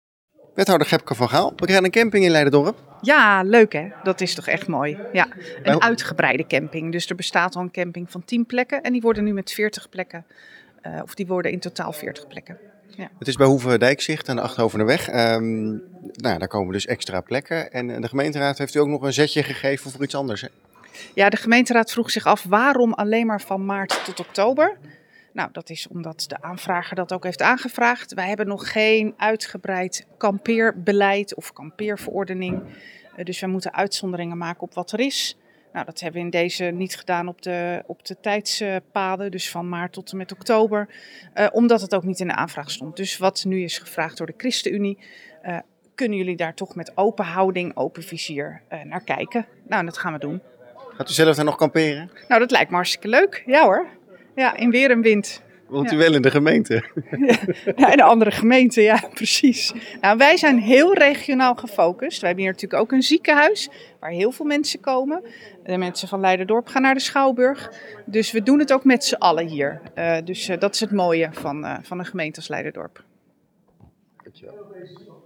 Wethouder Gebke van Gaal over de camping: